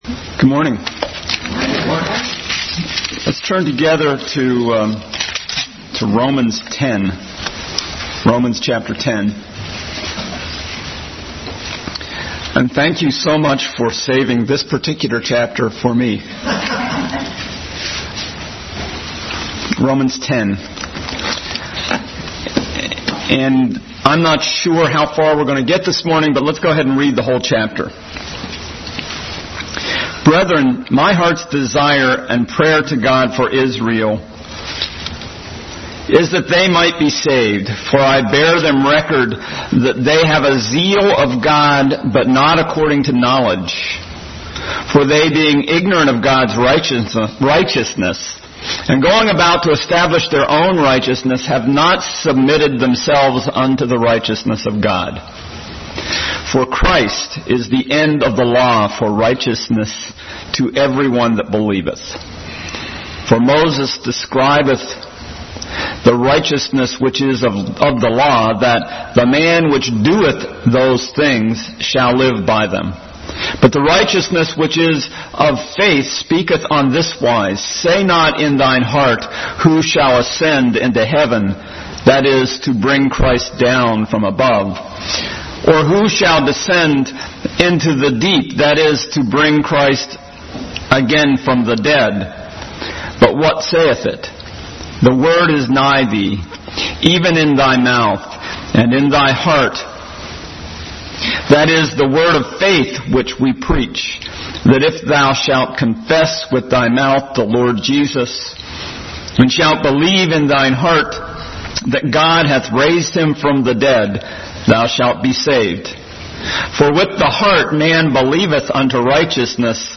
Bible Text: Romans 10:1-21, Deuteronomy 30:1-3, 11-14, 32:16-24 | Adult Sunday School continued study in the book of Romans.